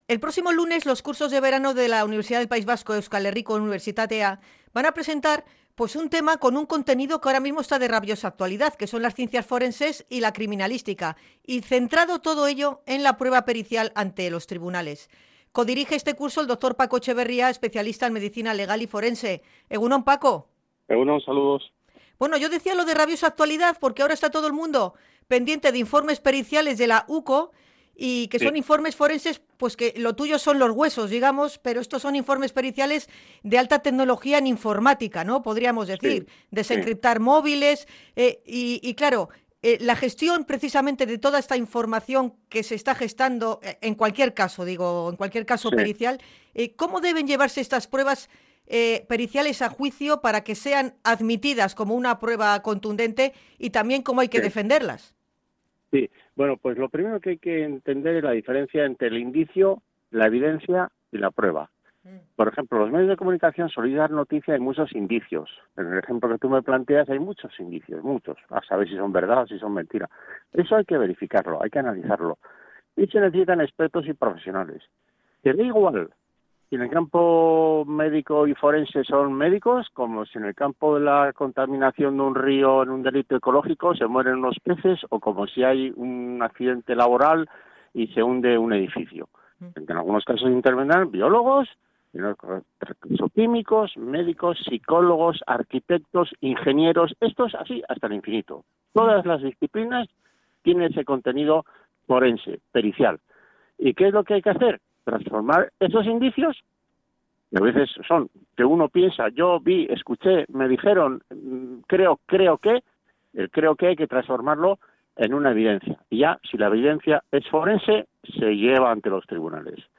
Hablamos con el prestigioso forense sobre Ciencias Forenses y Criminalística